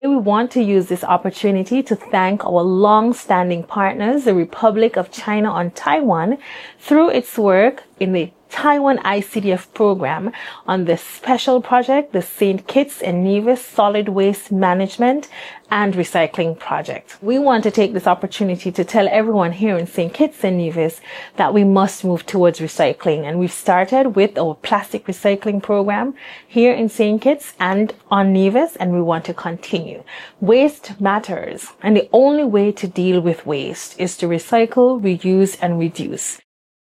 Federal Minister of Environment and Climate Action, the Hon. Dr. Joyelle Clarke, who addressed the Federation of St. Kitts and Nevis, said “waste matters”:
Federal Minister of Environment, Dr. Joyelle Clarke.